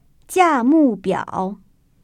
[jiàmùbiăo] 지아무뱌오  ▶